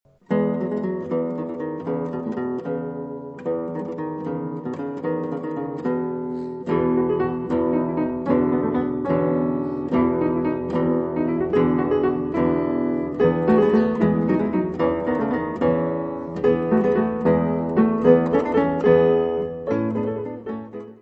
piano
guitarra.
Área:  Música Clássica